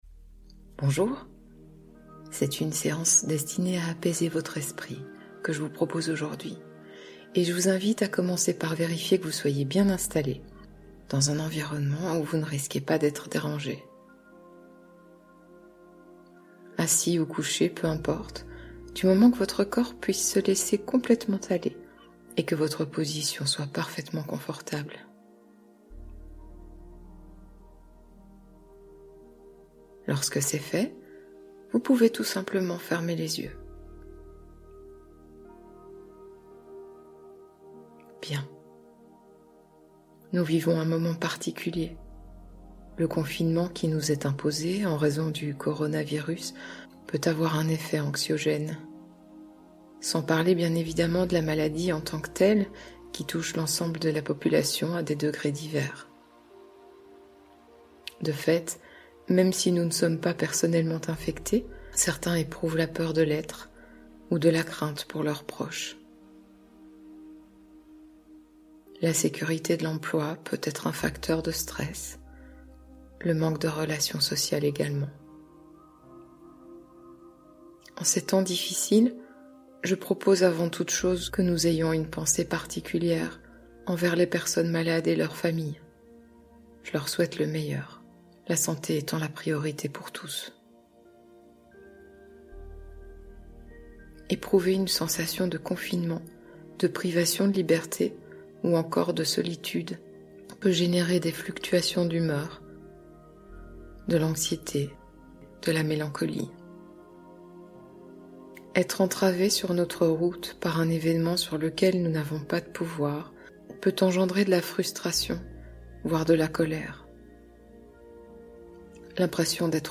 Libérez votre esprit du coronavirus et de l'anxiété du confinement (hypnose urgente)